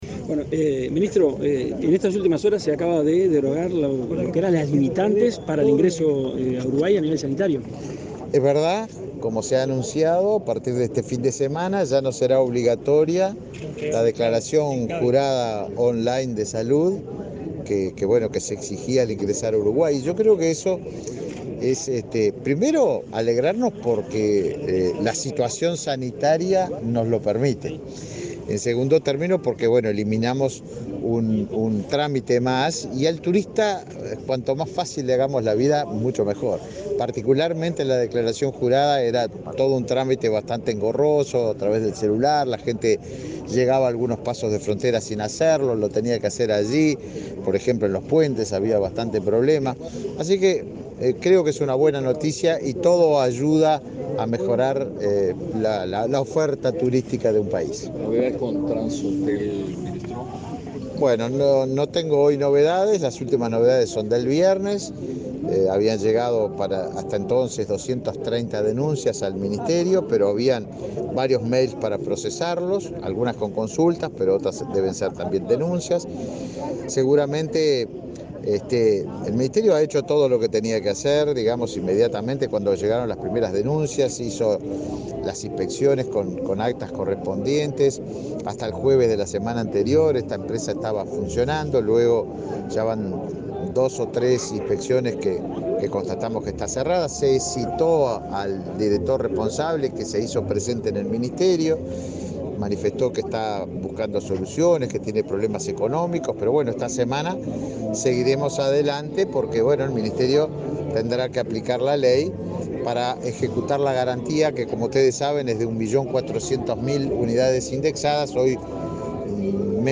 Declaraciones del ministro de Turismo
Declaraciones del ministro de Turismo 29/08/2022 Compartir Facebook X Copiar enlace WhatsApp LinkedIn El ministro de Turismo, Tabaré Viera, participó del lanzamiento de la temporada de avistamiento de ballenas en el balneario La Paloma, departamento de Rocha. Luego, dialogó con la prensa.